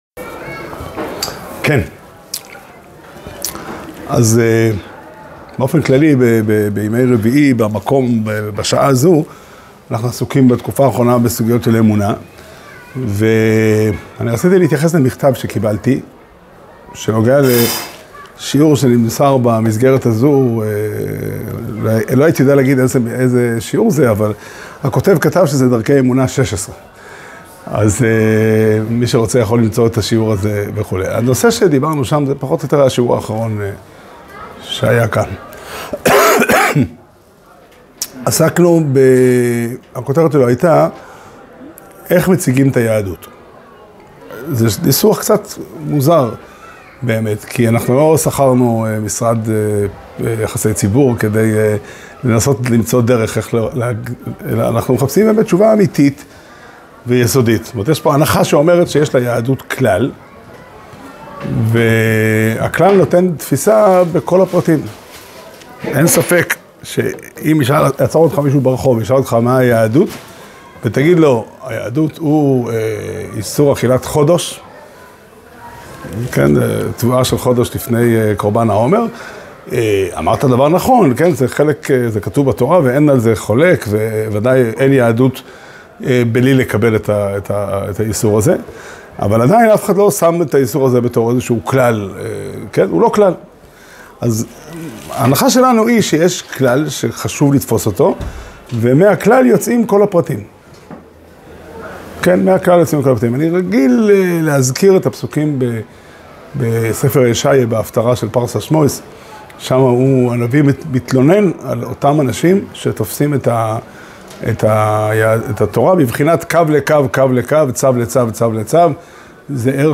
שיעור שנמסר בבית המדרש פתחי עולם בתאריך כ"ט טבת תשפ"ה